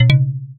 new pickup sound.
pickup.ogg